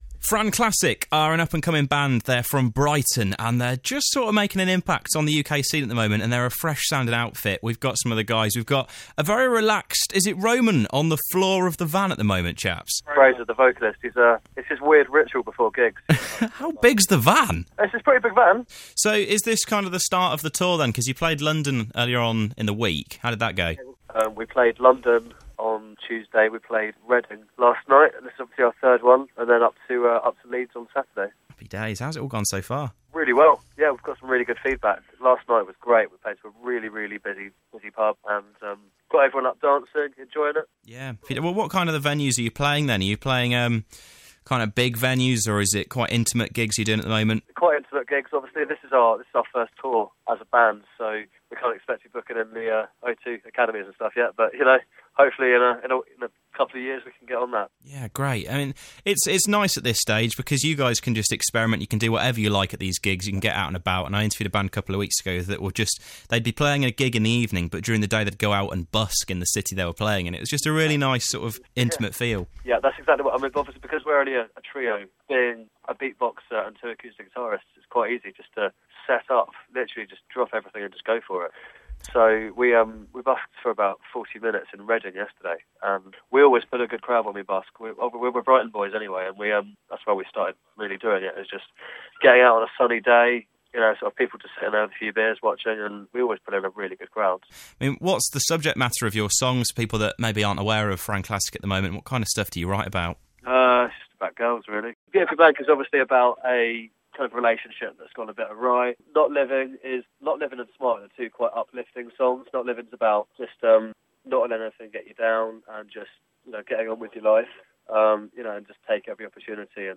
Brighton-based lads FranClassic have just put the button on a huge UK tour. Whilst they were discovering the country, the found some time to give us a call!